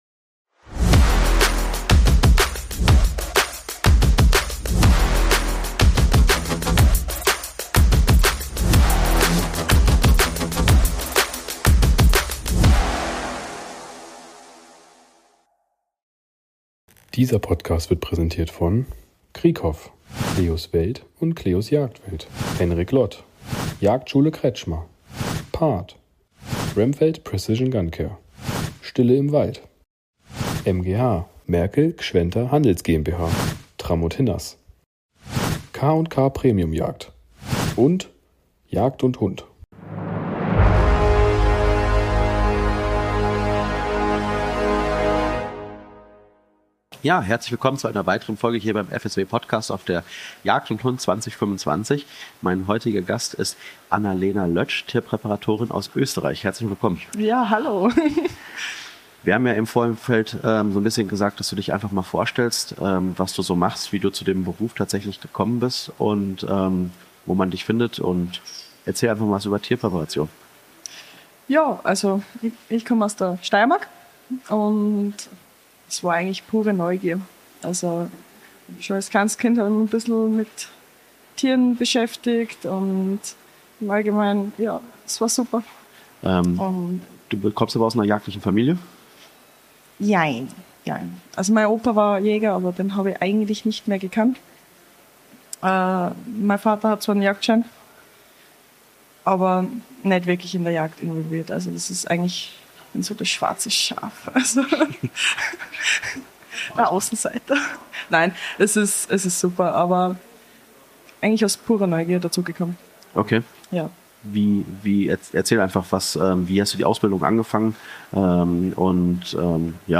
Willkommen auf der Jagd & Hund 2025!
Wir sind live auf Europas größter Jagdmesse unterwegs und sprechen mit spannenden Gästen aus der Jagdszene.